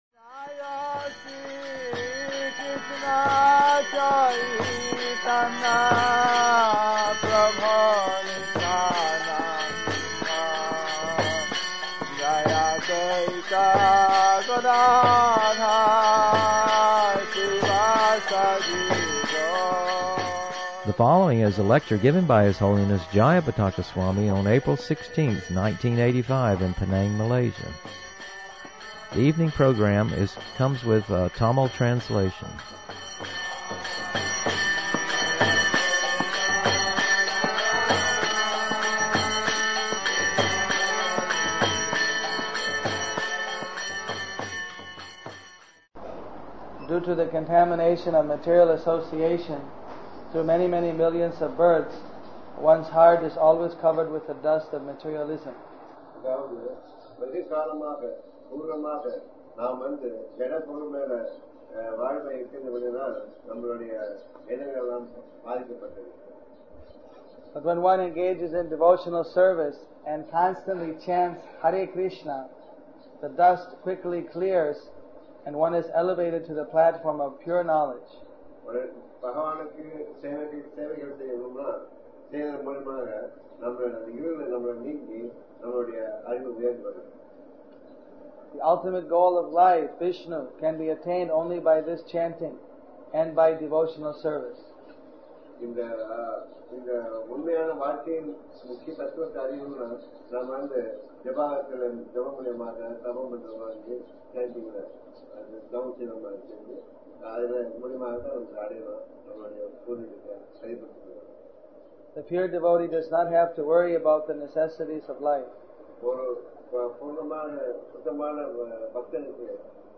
The evening program comes with a tamil translation.